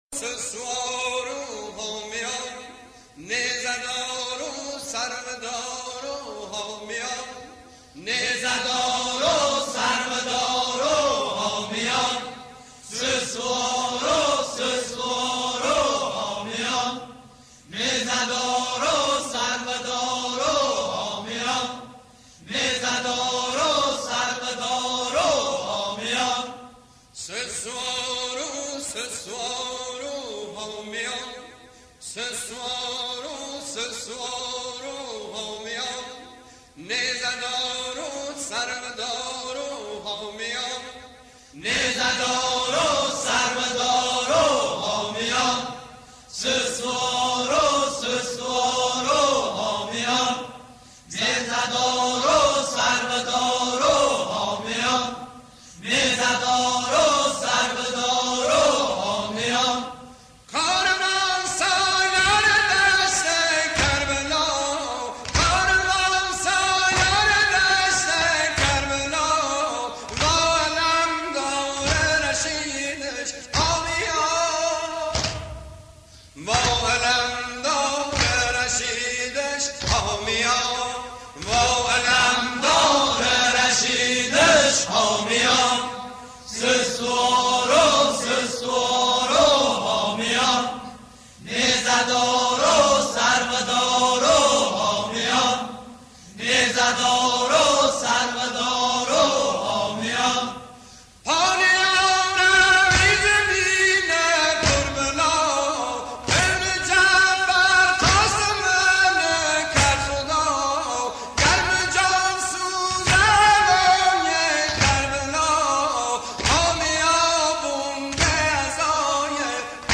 دانلود نوحه لری خرم آبادی ویژه مراسم سوگواری محرم